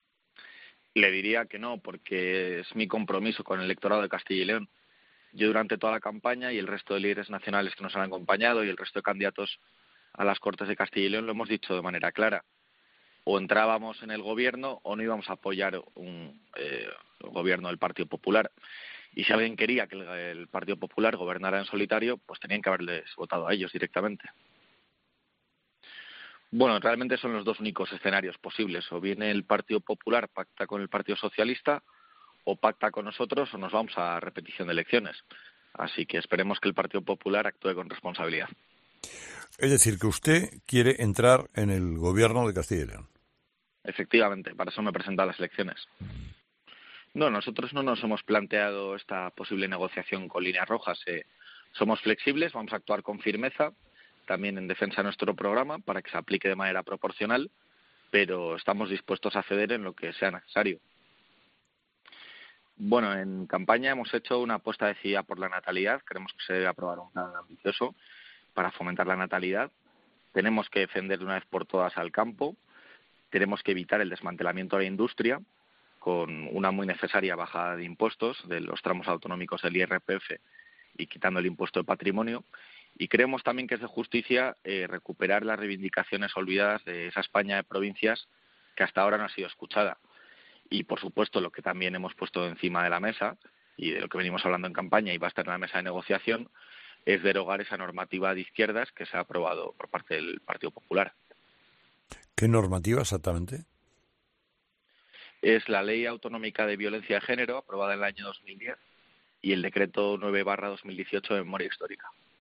La entrevista a García-Gallardo (Vox) en COPE, en cinco frases
El candidato de Vox a la presidencia de CyL ha conversado con Carlos Herrera en 'Herrera en COPE' para explicar el posicionamiento de su partido tras los comicios del 13-F